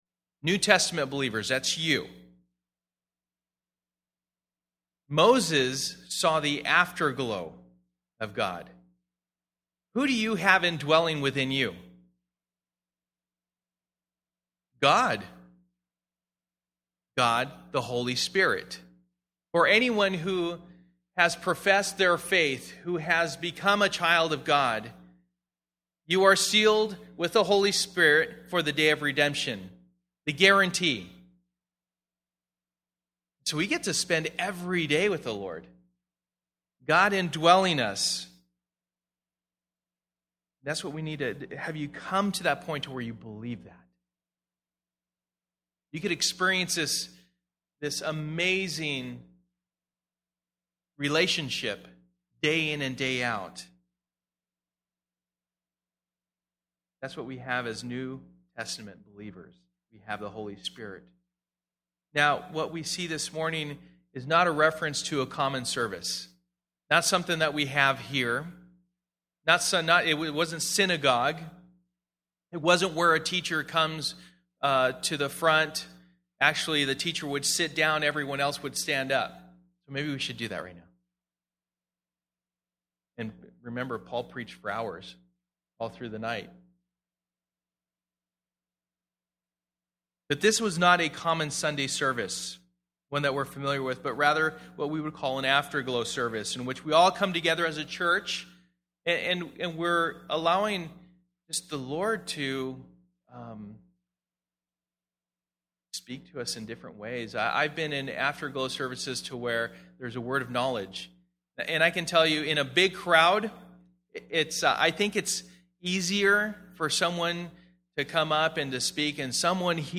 1 Corinthians 14:26-40 Service: Sunday Morning %todo_render% « Nehemiah